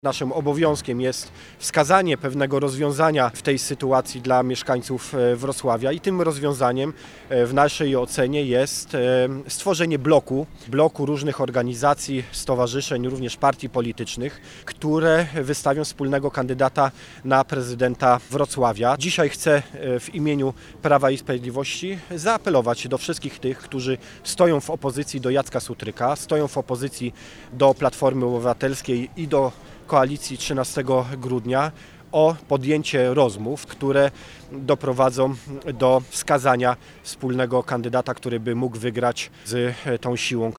Dlatego pojawił się pomysł, by środowiska opozycyjne rozpoczęły rozmowy w kierunku wyboru kandydata lub kandydatki obywatelskiej, mogącej zastąpić prezydenta Sutryka na urzędzie, mówi poseł na Sejm Paweł Hreniak.
02_posel-Hreniak.mp3